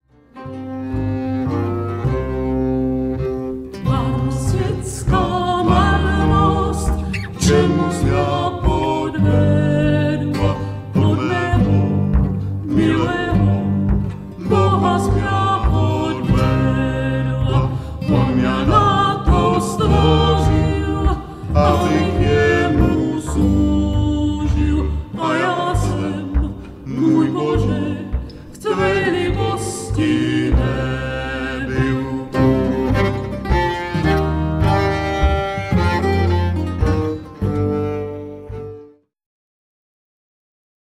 old moravian ballads
vocals, viola, spinettino, percussion